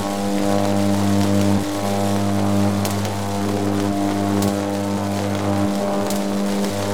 electric_sparks_lightning_loop5.wav